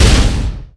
geomhwan_explo.wav